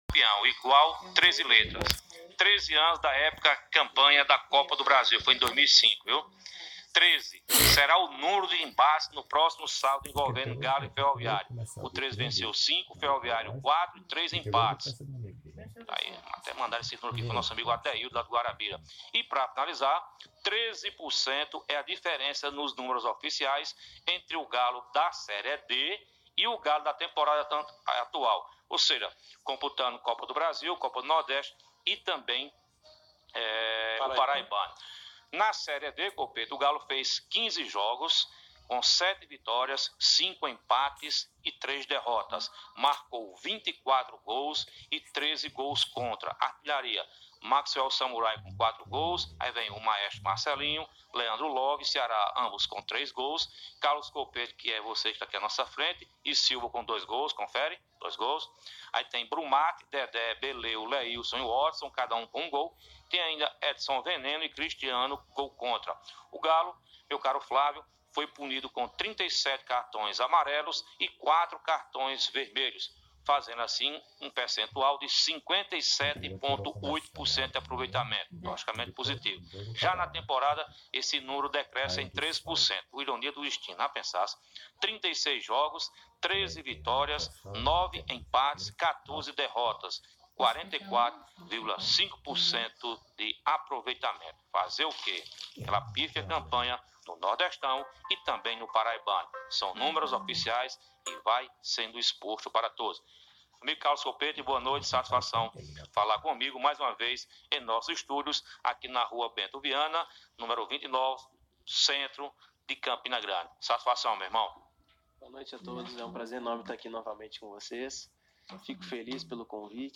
Em uma entrevista de quase meia hora